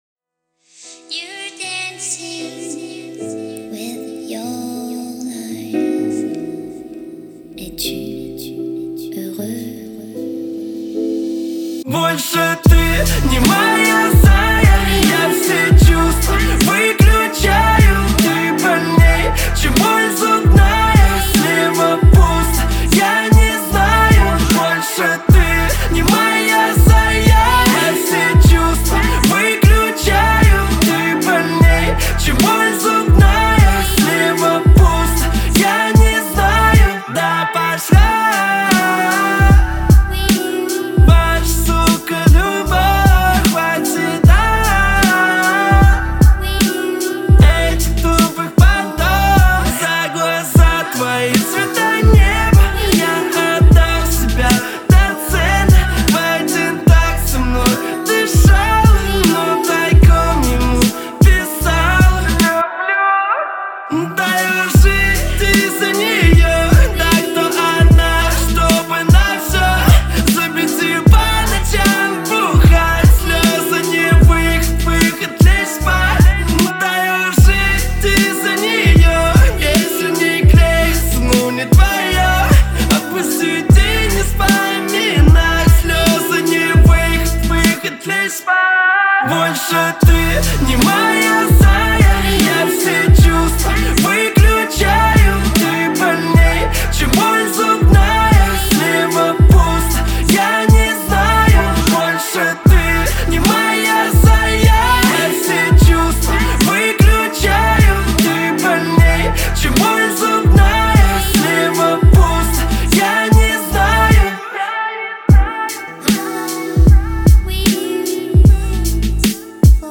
это яркая композиция в жанре поп